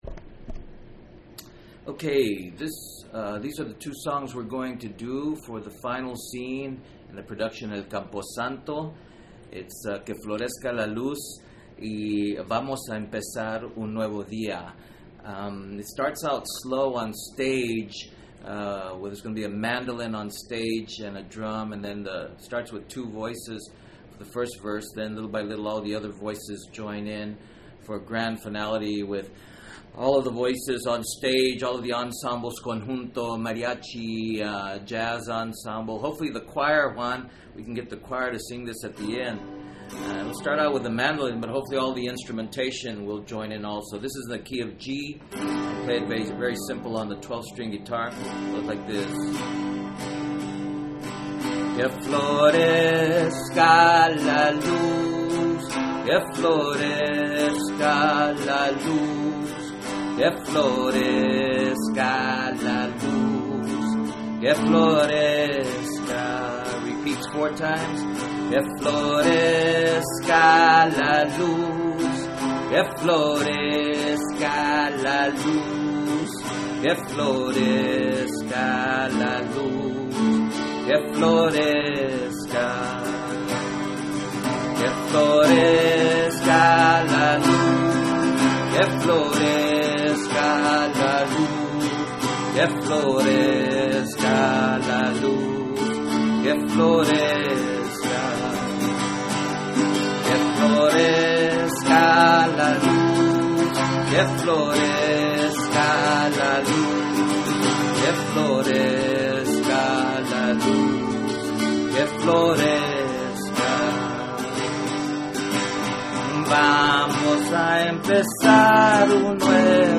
” a theater performance  in Fall 2009 in celebration of Dia de Los Muertos.
from → Audio, song